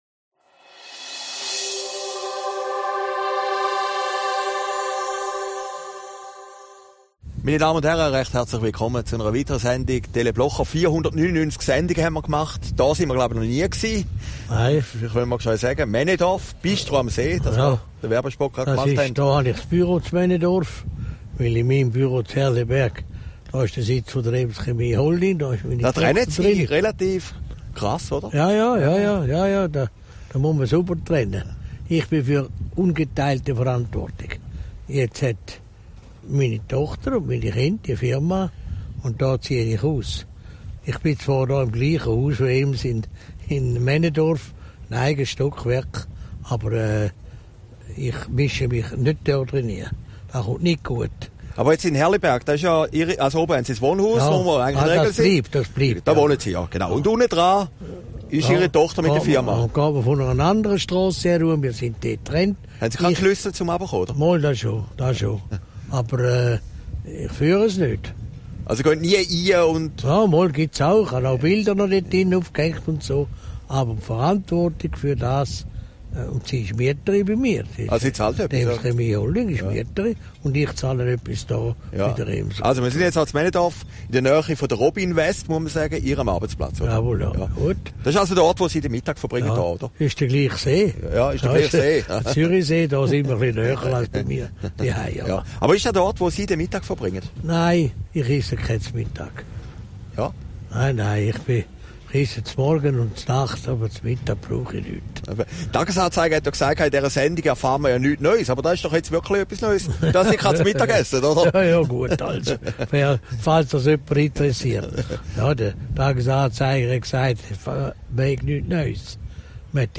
Aufgezeichnet in Männedorf, 23. März 2017